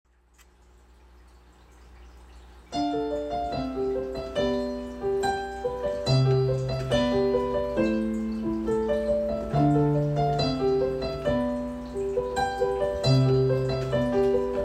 Testing piano sounds….. sound effects free download